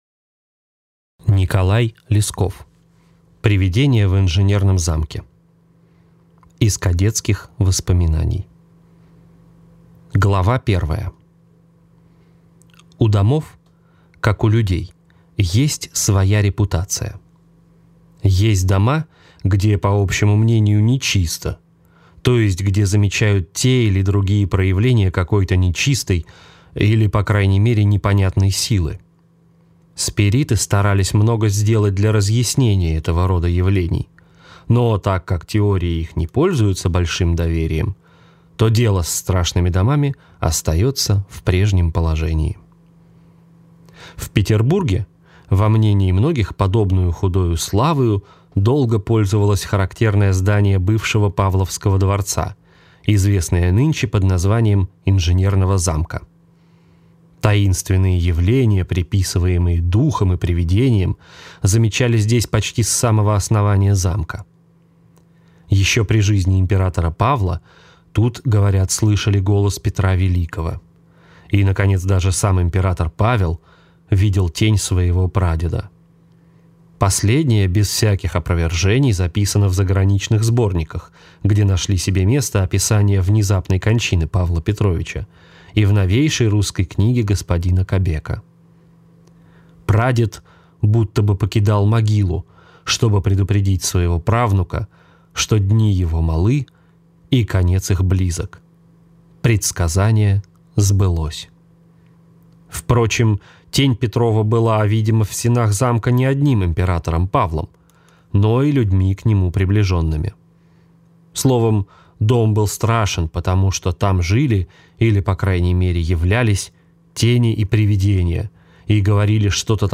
Аудиокнига Привидение в инженерном замке | Библиотека аудиокниг